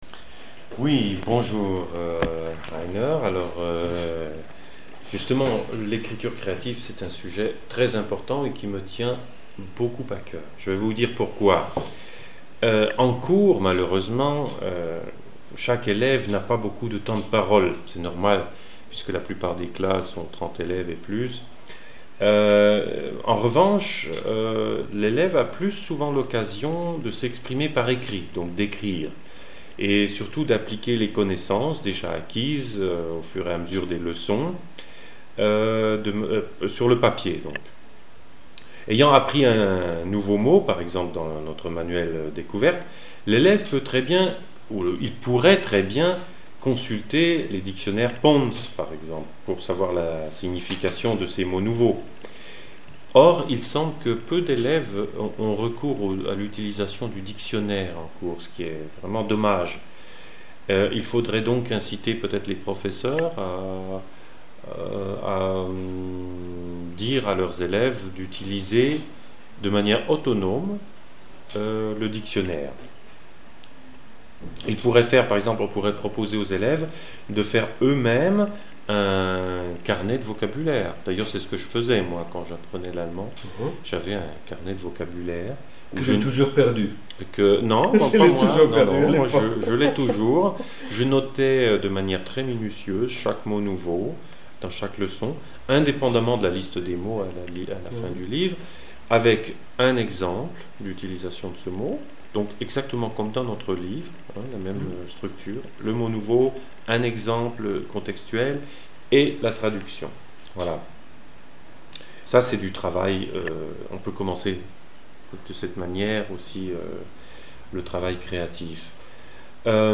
Das Gespräch